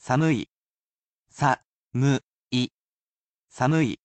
I am sure to read these aloud for you as not to leave you without a way to use them, sounding them out moji by moji.